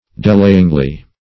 delayingly - definition of delayingly - synonyms, pronunciation, spelling from Free Dictionary Search Result for " delayingly" : The Collaborative International Dictionary of English v.0.48: Delayingly \De*lay"ing*ly\, adv.
delayingly.mp3